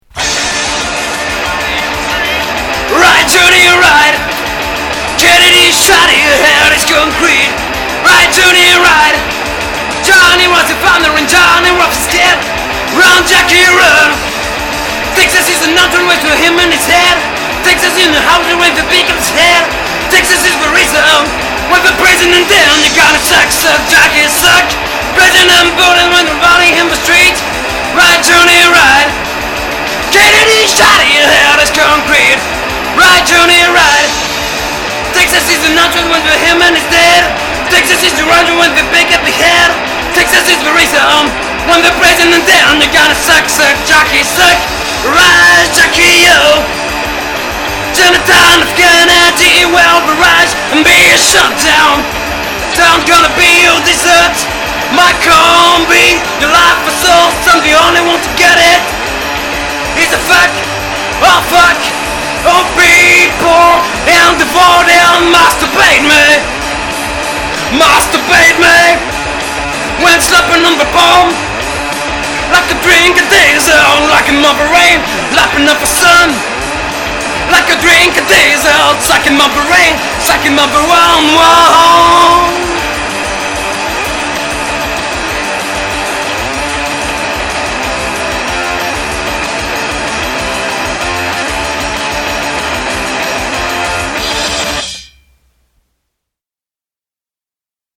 Attention c'est plutôt aggressif comme son